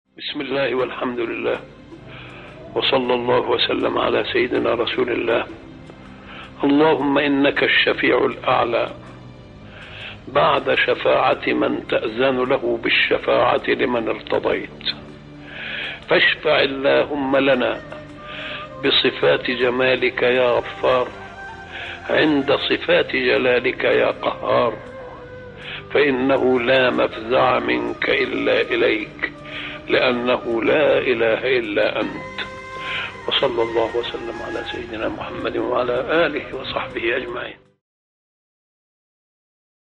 دعاء خاشع يبدأ بالحمد والثناء على الله والصلاة على رسول الله، ويتوسل إلى الله بصفات الجلال والجمال ويسأل الشفاعة. يعبر الدعاء عن حالة من الأنس بالله والانكسار بين يديه مع اليقين بأنه لا معبود بحق سواه.